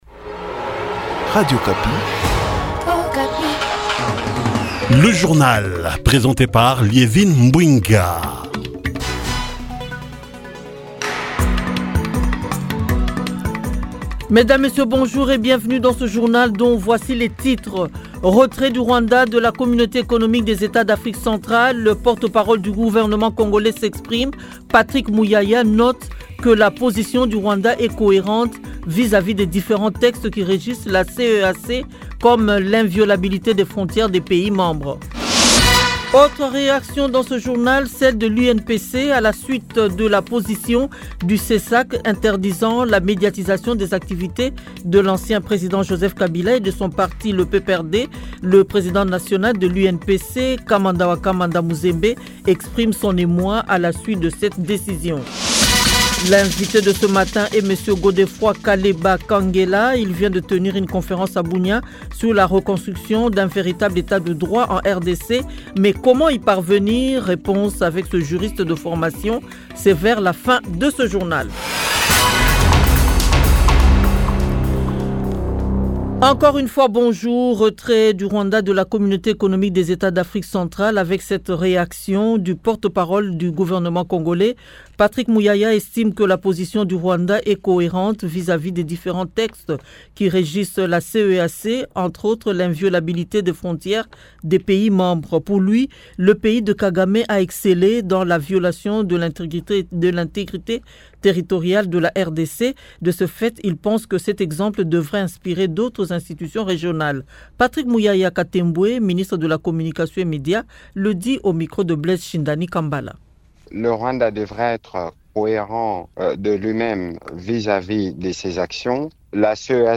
Journal 6h et 7h lundi 9 juin 2025